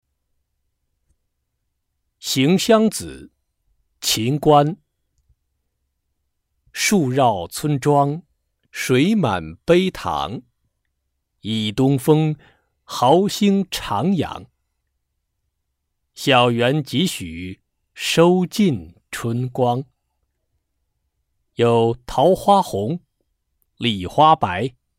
九年级语文上册 第六单元 课外古诗词诵读《行香子》课文朗读素材